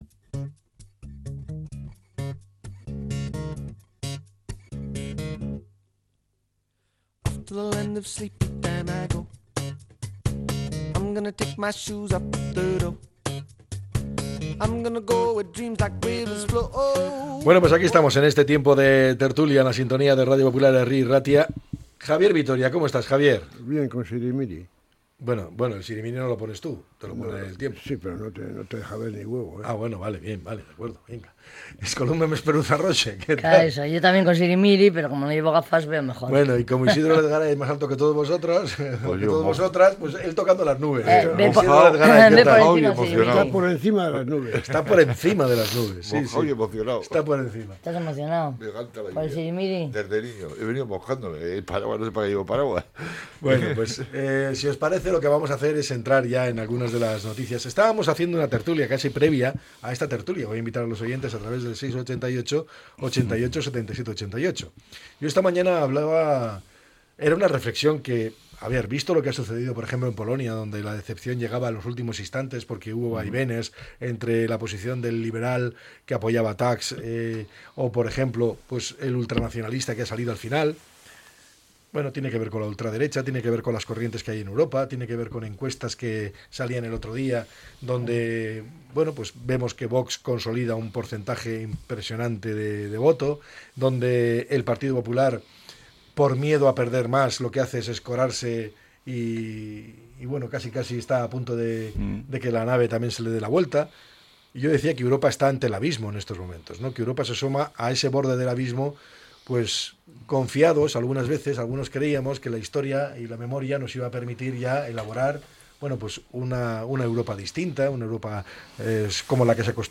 La tertulia 03-06-25.